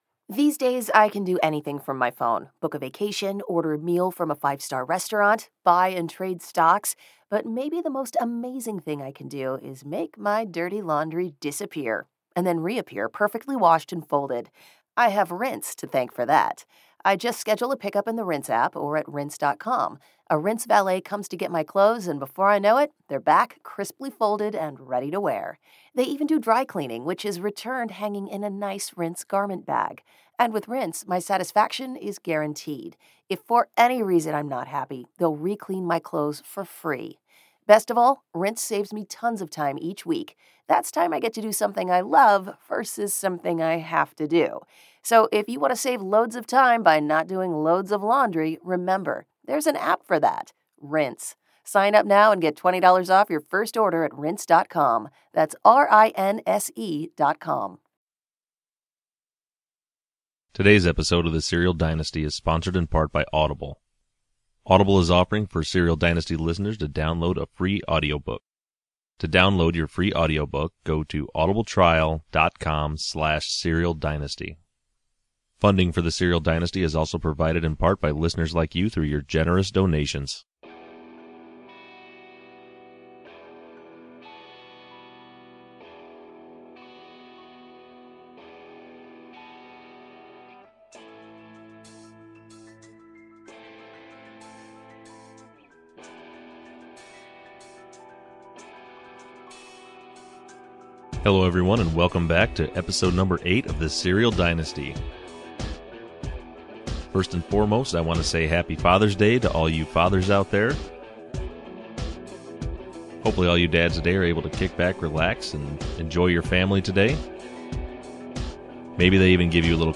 Interview with Rabia Chaudry, and Undisclosed Addendum 5 Analysis